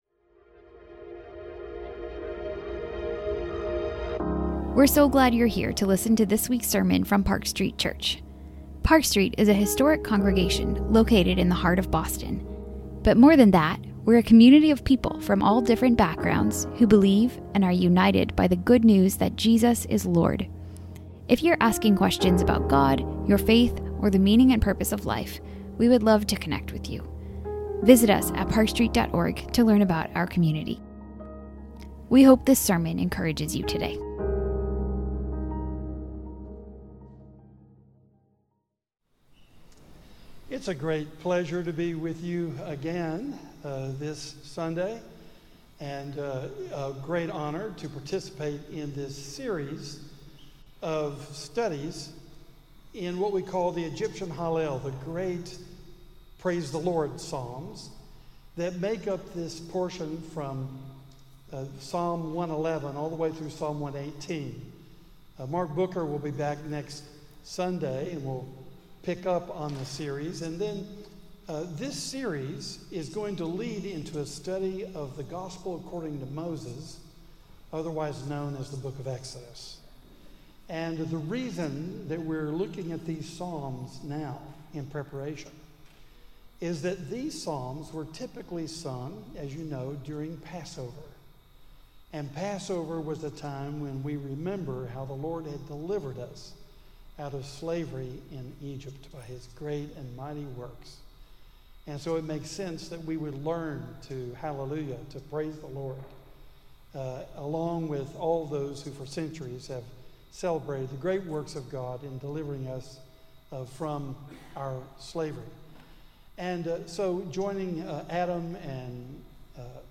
This sermon considers John's vision of the core of reality: the heavenly throne room in which there is unceasing worship of the one seated on the throne. Through this breathtaking vision, we are invited to think more deeply about - and to enter into - that worship for which we were made.